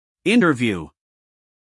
interview-us-male.mp3